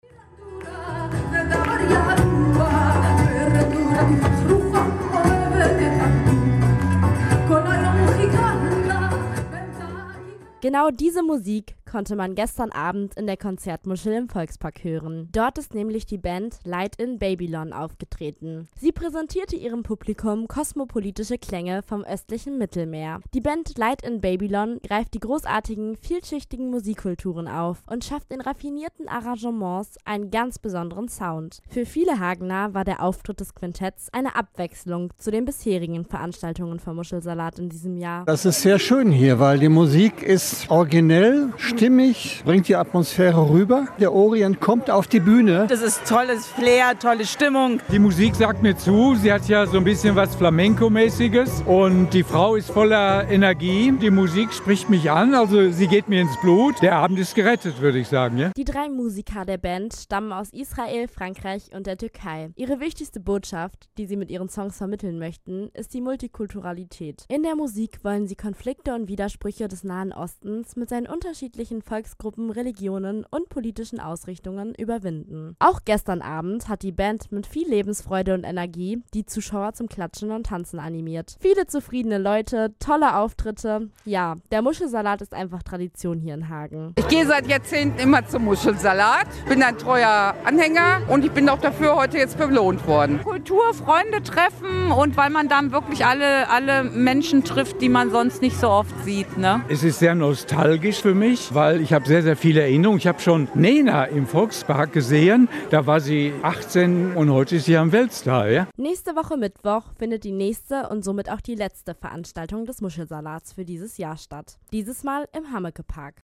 Am 26. Juli fand in der Konzertmuschel im Volkspark die vorletzte Veranstaltung des Hagener Muschelsalates statt.